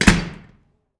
DOOR CL A -S.WAV